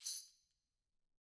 Tamb1-Shake_v1_rr2_Sum.wav